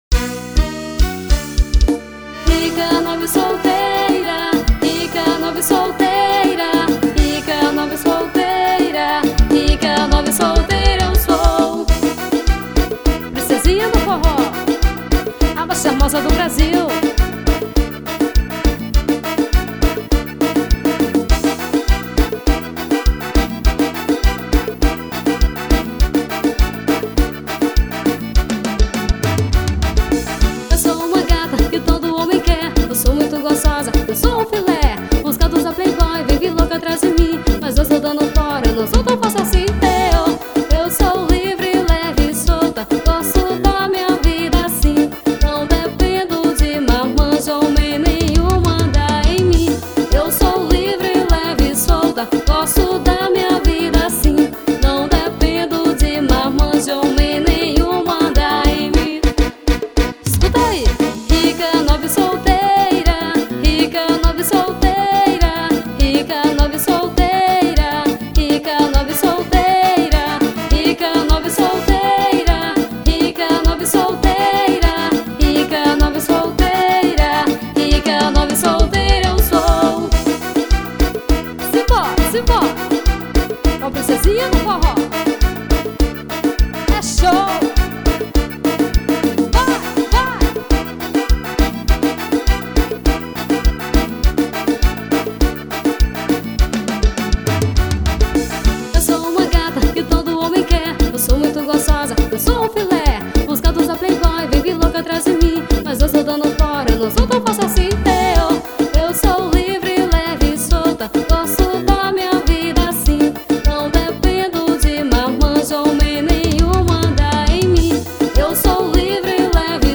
forro estorado.